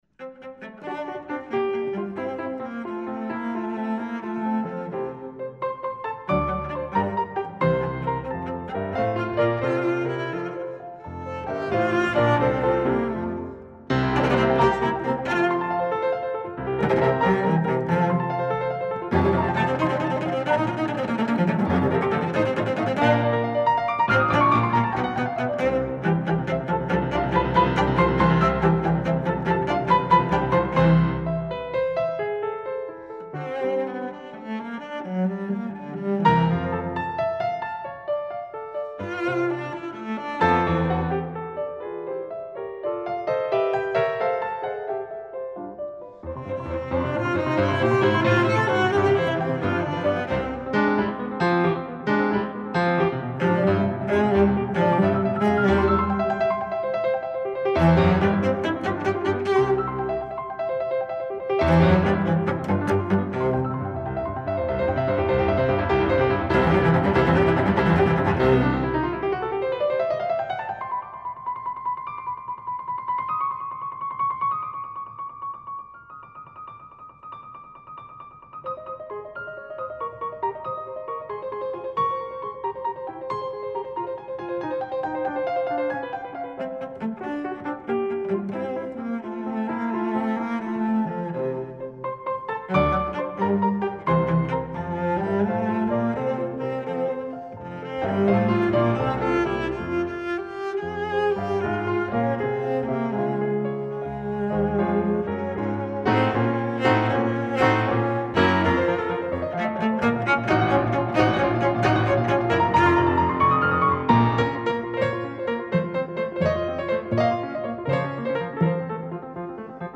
Voicing: Cello and Piano